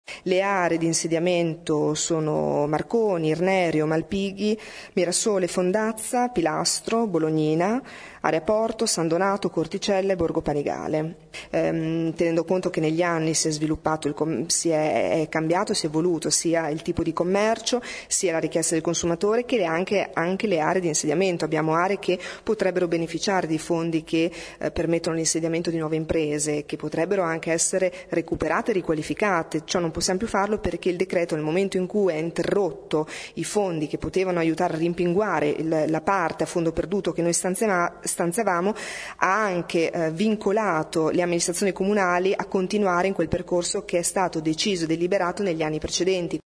Ascolta l’assessore al Commercio Nadia Monti